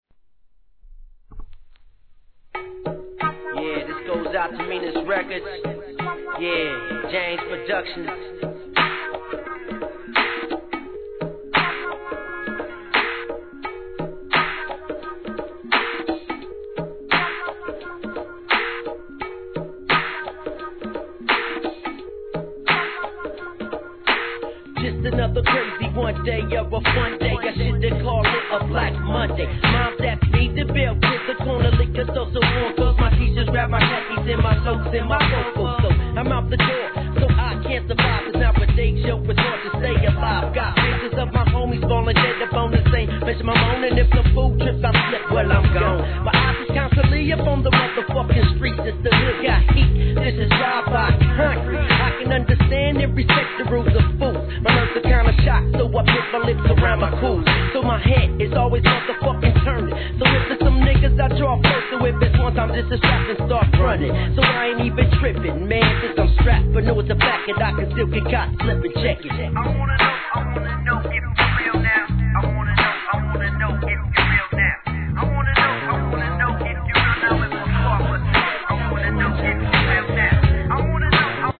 G-RAP/WEST COAST/SOUTH
絶妙なコーラスが切なくCHILIN'なマッタリSHITで聴かせる